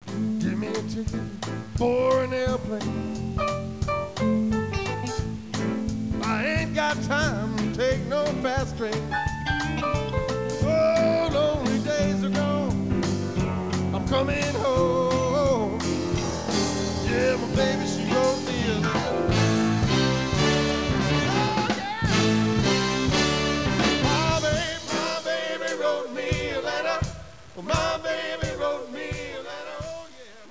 This CD was recorded during the winter/blizzard of '96'.
Lead Vocal